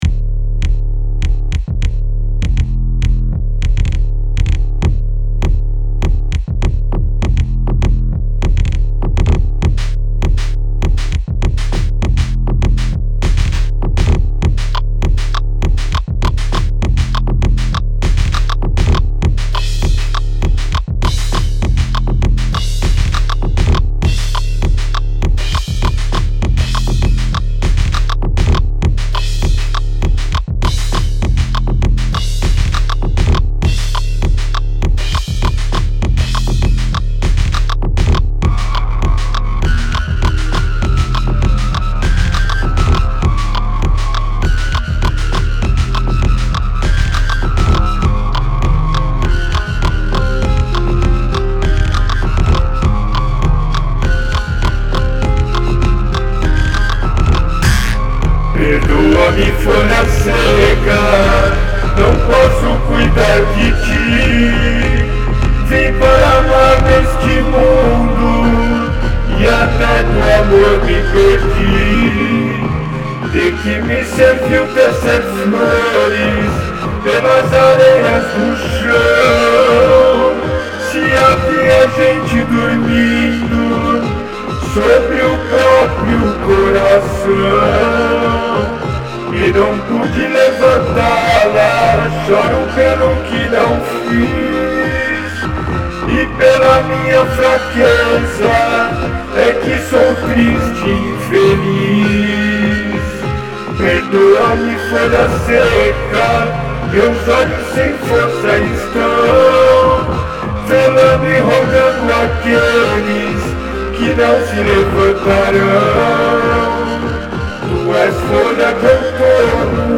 EstiloExperimental